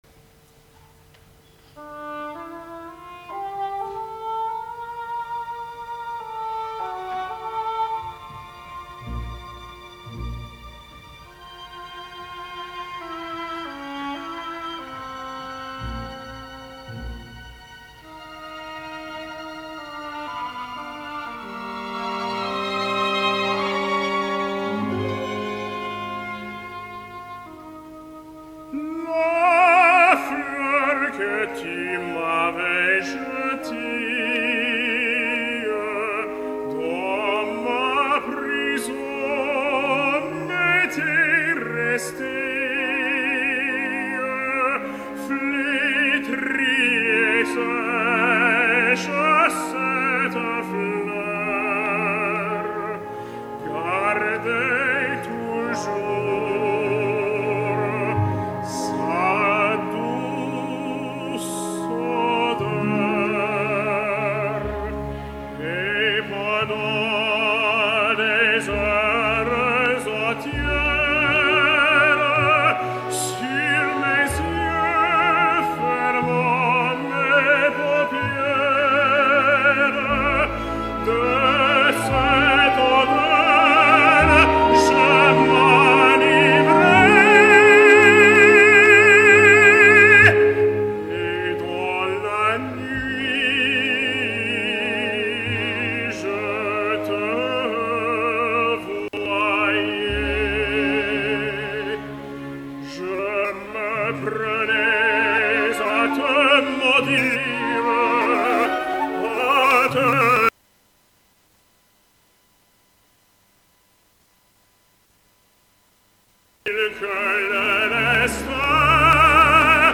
L’escoltarem en àries prou conegudes, del gran repertori i en una gravació provinent d’un concert en directa, per tant podreu apreciar les virtuts d’una veu in un cantant, de la mateixa manera que evidenciareu que no tot està assolit i que encara queden coses per polir.
Ara , seguint amb el repertori francès, escoltarem la famosa ària de Don José “La fleur que tu m’avais jetee”. Ja us aviso que la gravació en aquest fragment està plena de talls i deficiències, però he volgut que l’escoltéssiu, ja que paga la pena fixar-se en com Hymel fa front als diversos reptes que aquesta ària planteja al tenor.
Tots els fragments provenen d’un concert celebrat el dia 9 de gener de 2013 a Jerusalem, l’orquestra està dirigida per Frédéric Chaslin